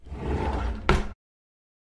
Close Drawer.wav